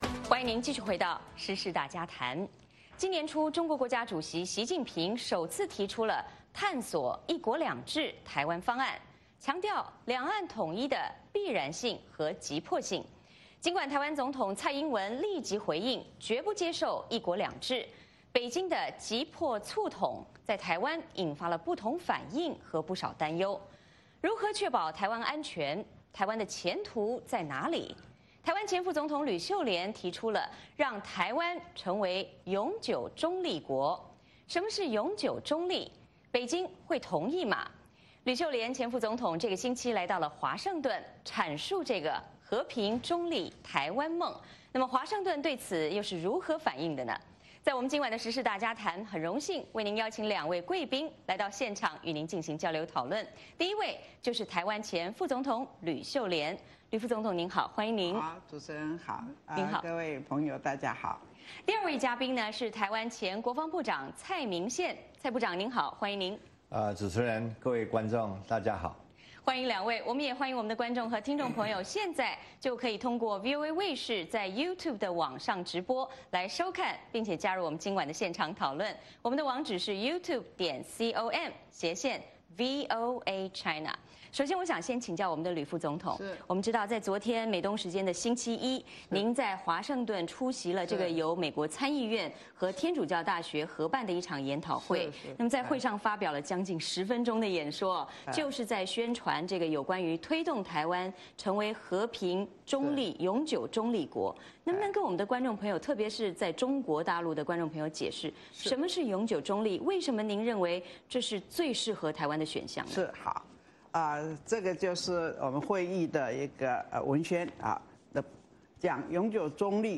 时事大家谈：台湾如何“永久中立”？专访台湾前副总统吕秀莲、前防长蔡明宪